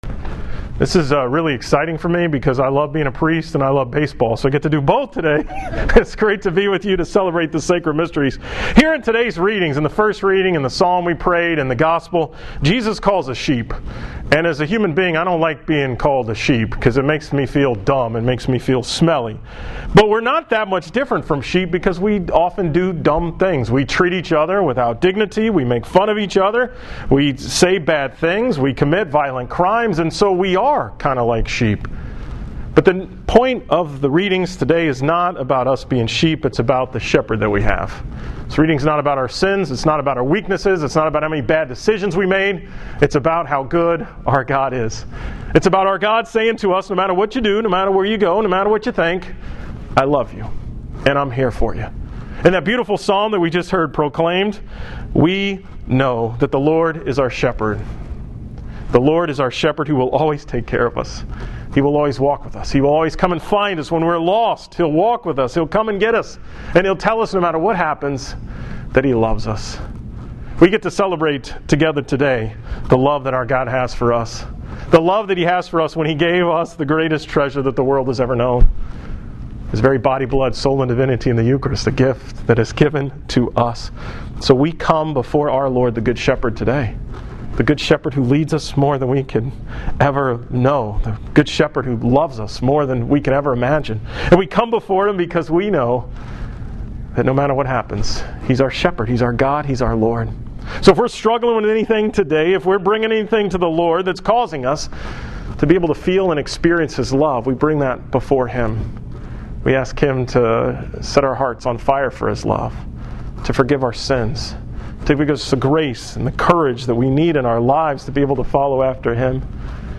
A very brief homily from the Mass at Minute Maid Park before the Astros-Rangers game on July 19th, 20115